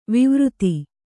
♪ vivřti